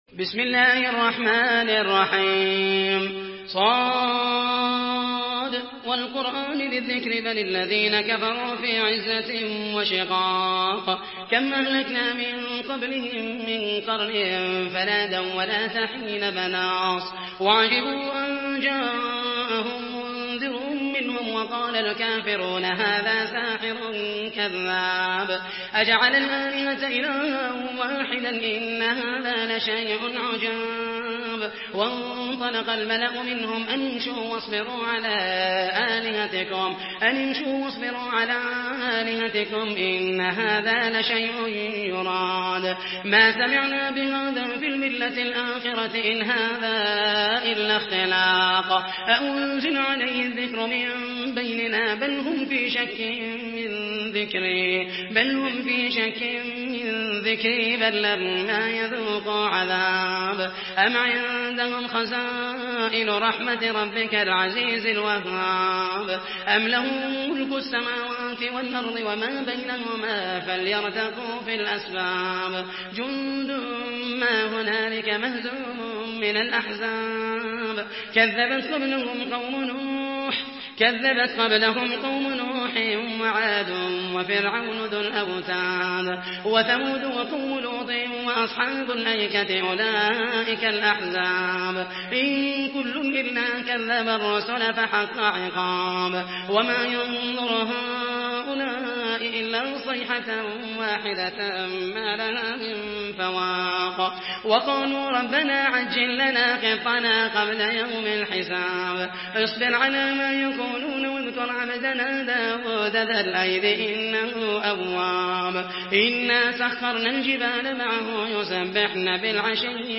Surah Sad MP3 by Muhammed al Mohaisany in Hafs An Asim narration.
Murattal Hafs An Asim